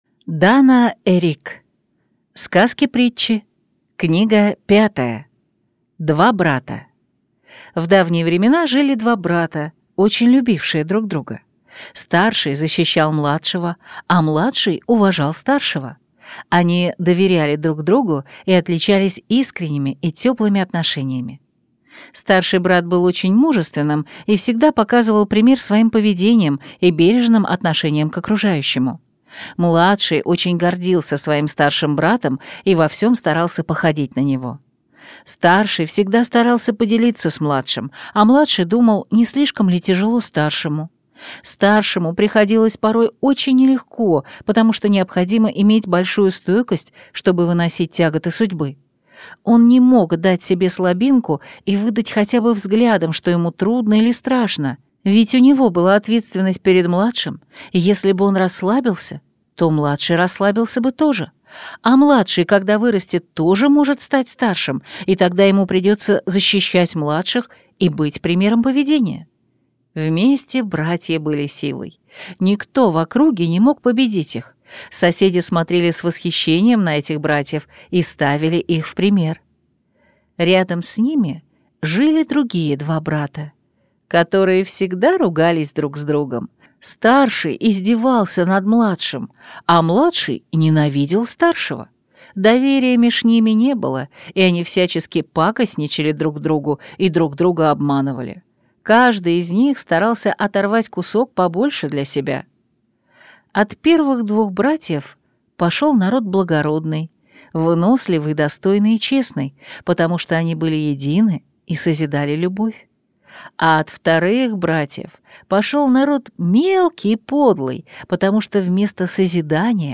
Аудиокнига Сказки-притчи. Книга 5 | Библиотека аудиокниг